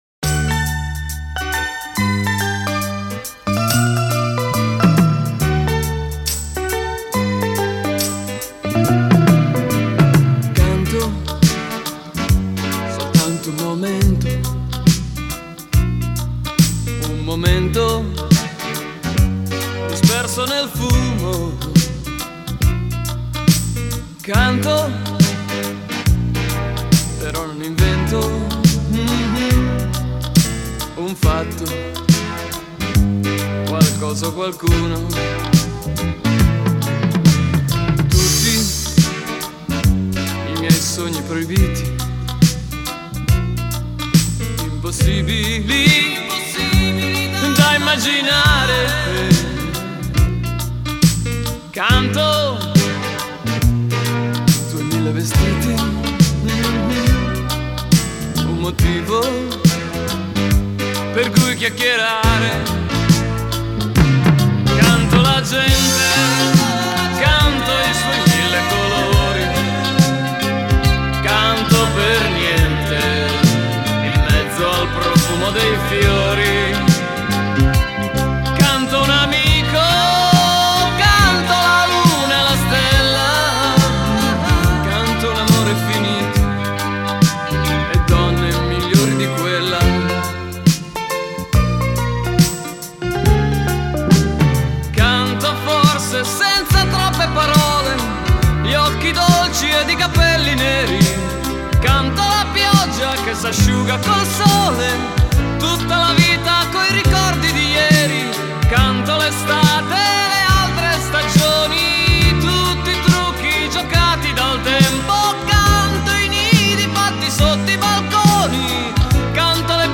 tastiere
batteria elettronica
cori
registrato nel Marzo 1985 allo Studio Bip’s – Milano